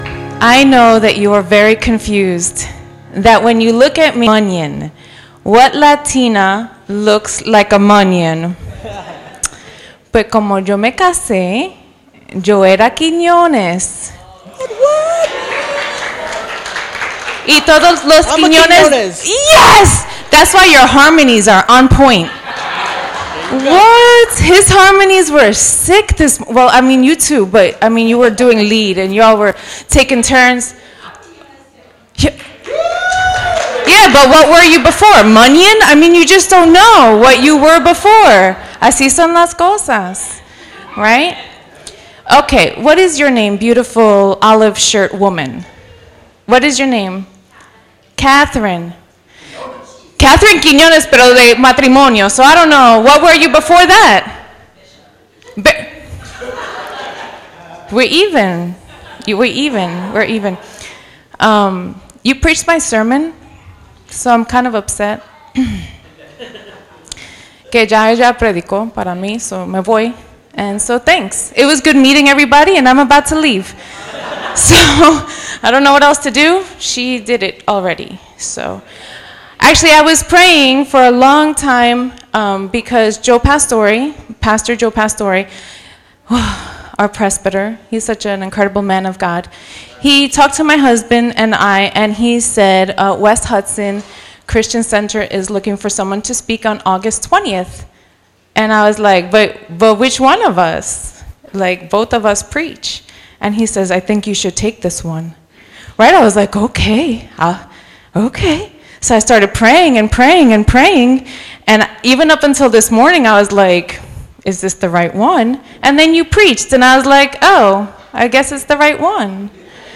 A wonderful sermon